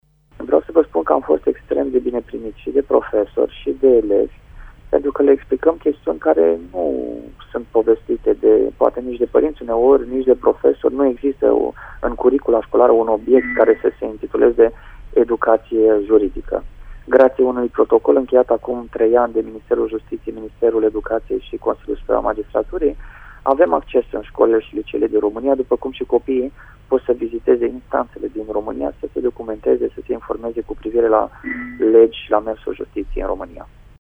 Cum a fost primit de copii, judecătorul Cristi Danileţ?